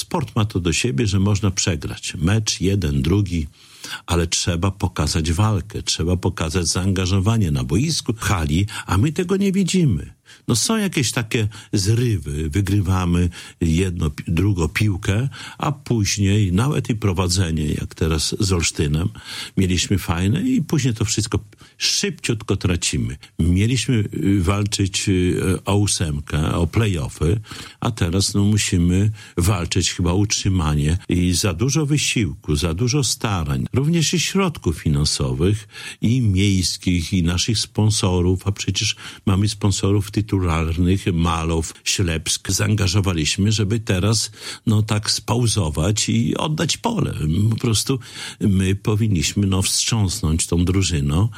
– Powinniśmy wstrząsnąć drużyną – mówi o Ślepsku Malow Suwałki Czesław Renkiewicz, prezydent miasta. Włodarz nie kryje niezadowolenia z wyników suwalskich siatkarzy.
Jak powiedział w Audycji z Ratusza w Radiu 5, kibice narzekają, że wiele elementów w grze nie funkcjonuje.